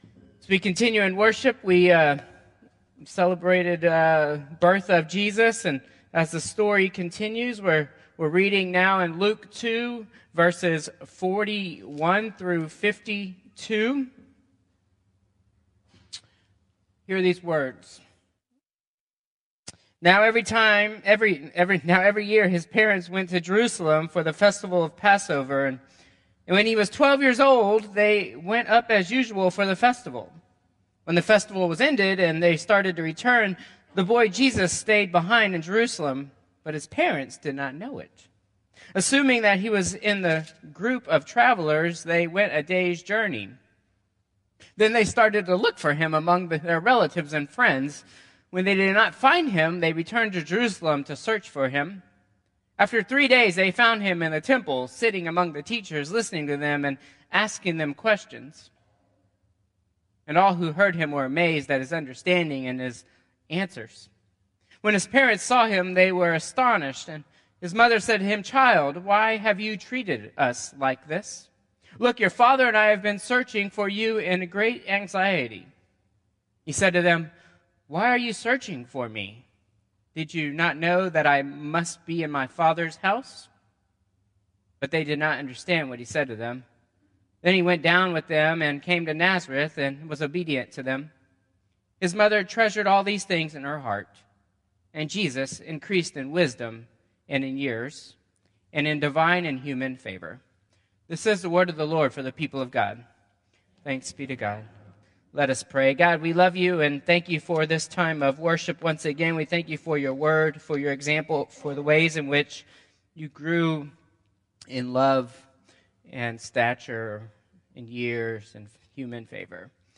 Combined Service 12/29/2024